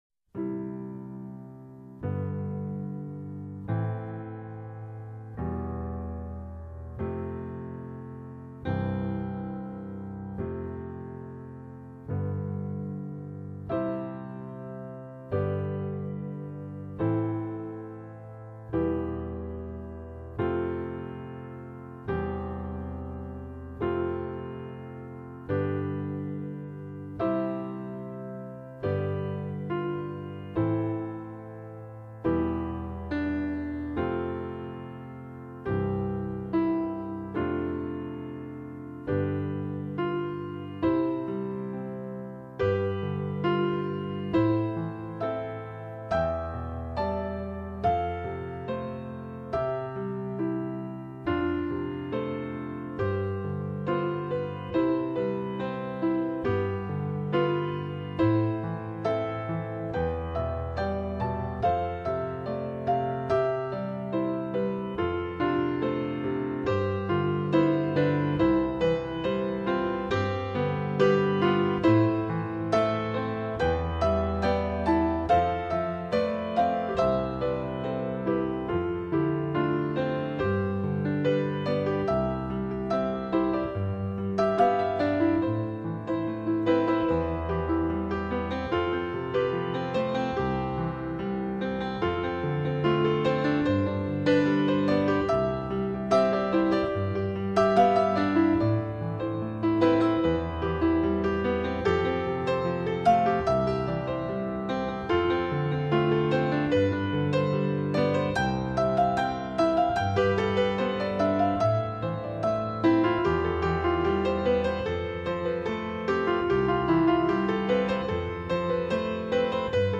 钢琴专辑
除了令人陶醉的美丽旋律，更使人惊奇的是音乐中在键盘上跳动着的灵感。